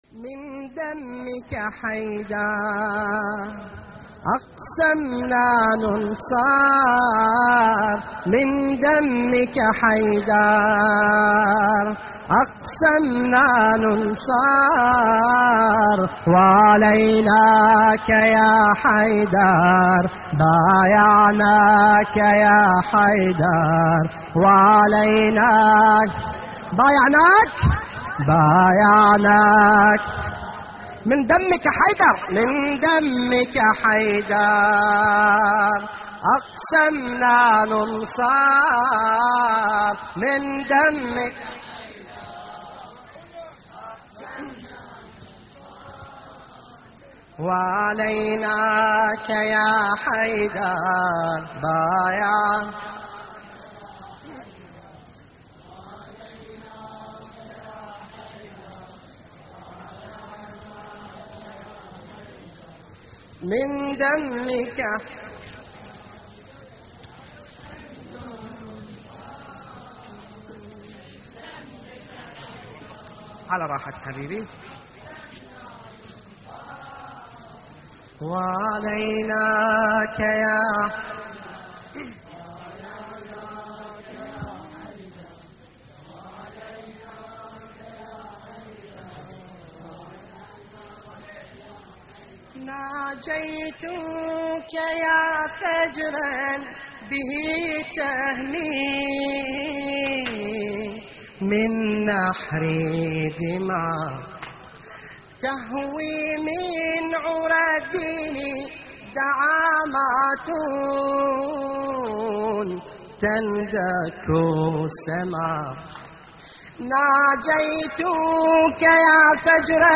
تحميل : من دمك حيدر اقسمنا ننصر 1 / مجموعة من الرواديد / اللطميات الحسينية / موقع يا حسين